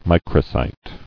[mi·cro·cyte]